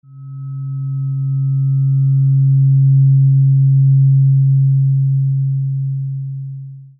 OM 136.1Hz Weighted Tuning Fork
Handcrafted OM 136.1Hz Tuning Fork made from high-grade aluminium, providing a long and enduring tone.
The handcrafted tuning fork is electronically tuned at 20°C and is made from high-grade aluminium, providing long and enduring notes.
OM-136.1Hz-Tuning-Fork.mp3